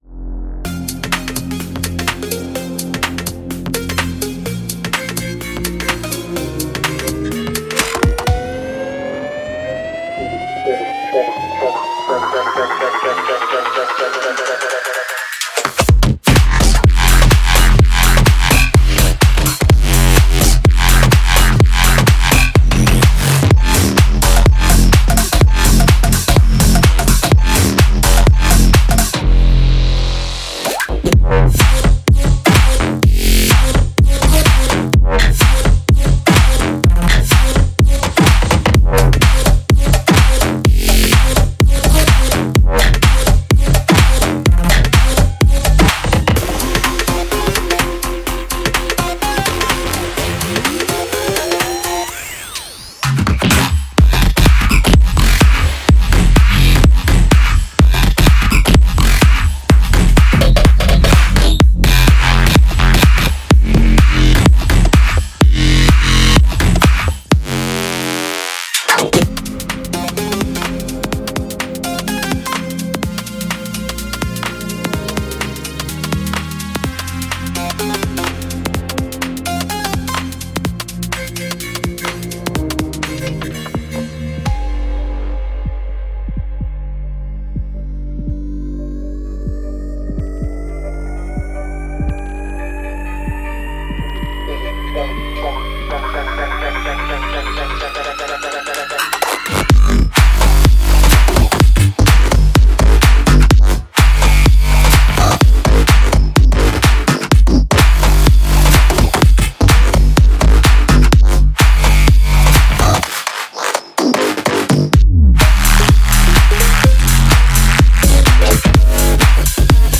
通过创新，未来感的声音创造出残酷的，令人难以置信的水滴
从Sidechained Bass Loops到创意打击乐再到Filler Loops，我们确保已包含您需要的所有声音！
低音屋和G屋无与伦比。